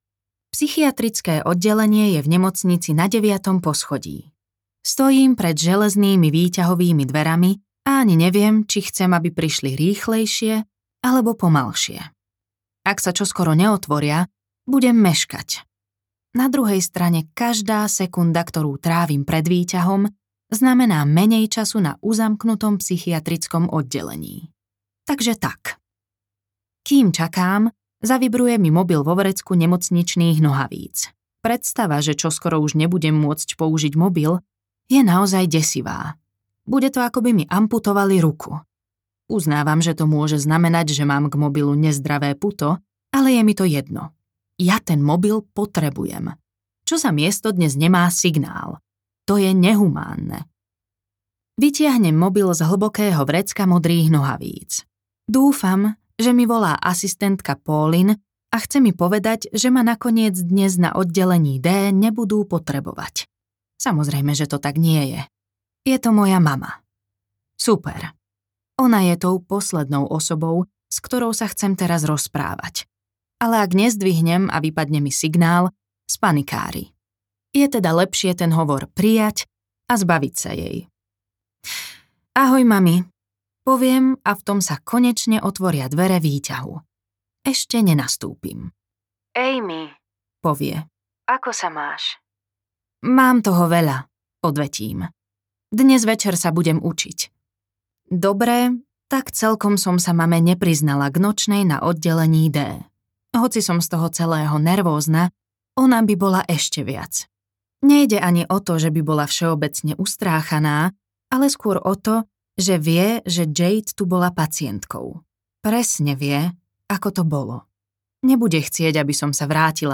Oddelenie D audiokniha
Ukázka z knihy